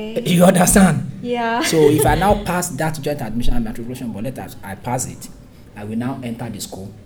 S1 = Bruneian female S3 = Nigerian male
It is indeed spoken very fast, and we would not be able to decipher it without the help of S3.